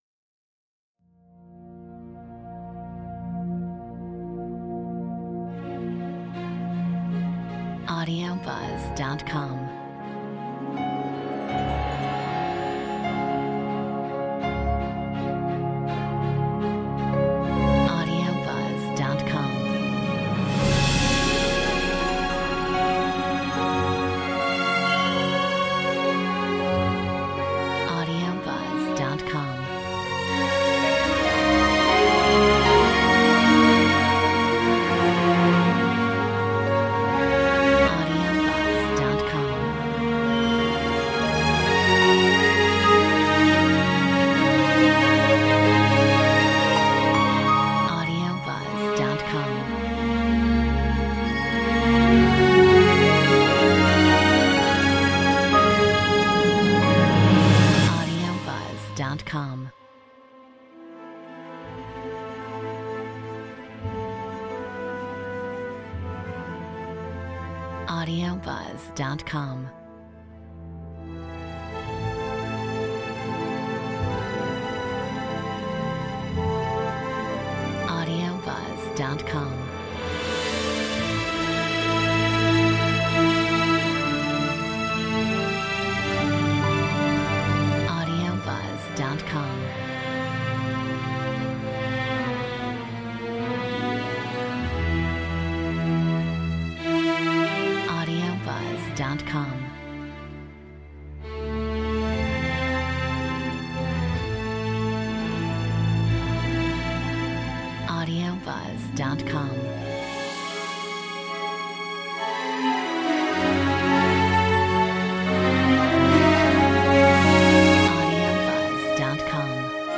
Metronome 80